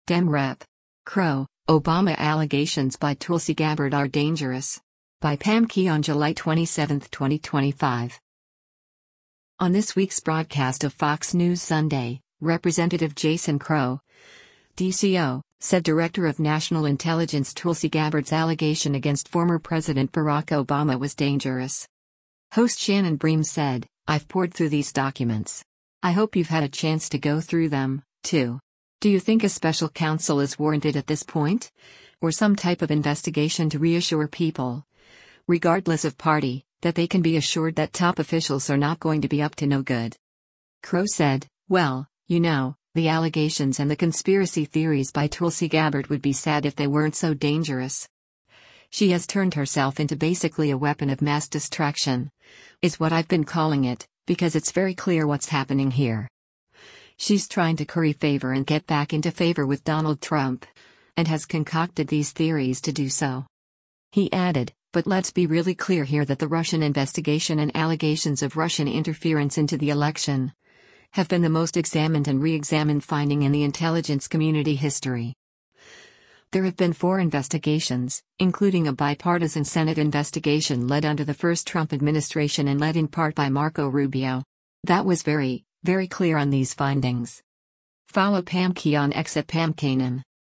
On this week’s broadcast of “Fox News Sunday,” Rep. Jason Crow (D-CO) said Director of National Intelligence Tulsi Gabbard’s allegation against former President Barack Obama was “dangerous.”